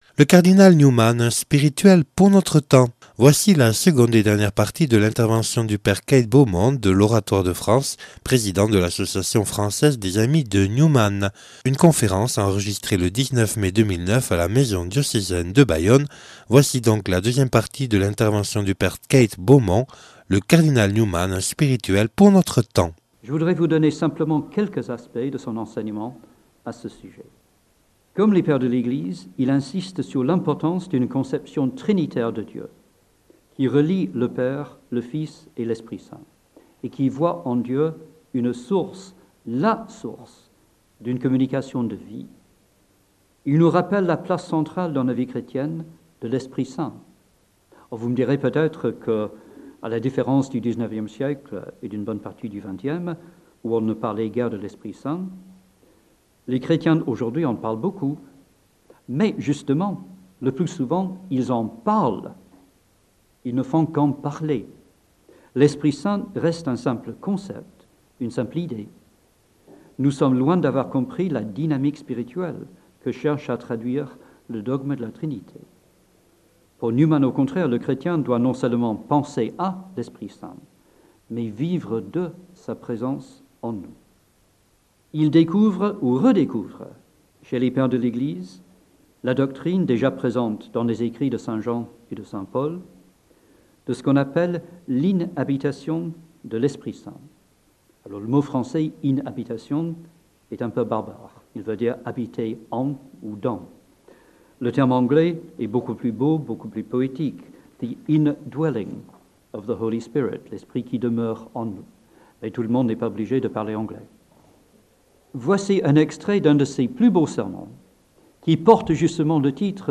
(Enregistré le 19/05/2009 à la maison diocésaine de Bayonne).